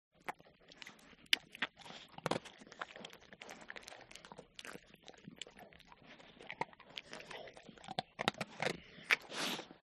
دانلود صدای غذا خوردن سگ و ملچ ملوچ کردن آن از ساعد نیوز با لینک مستقیم و کیفیت بالا
جلوه های صوتی